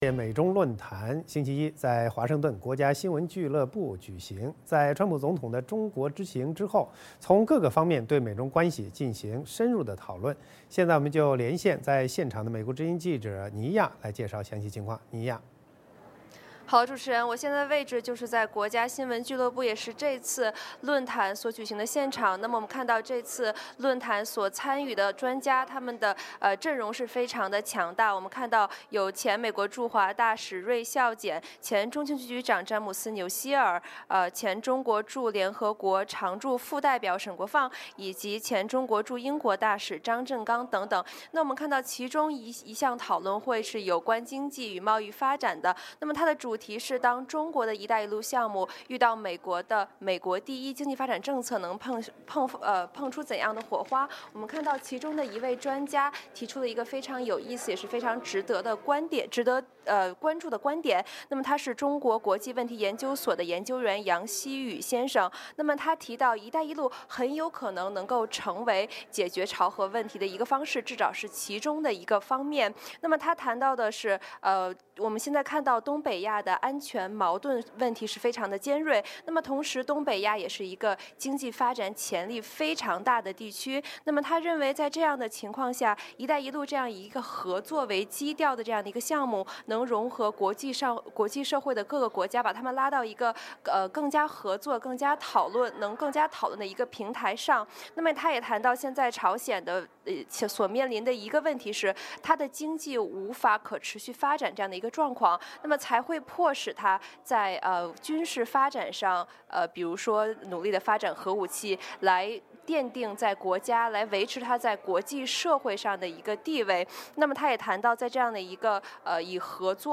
VOA连线：美中论坛讨论后国事访问两国关系进程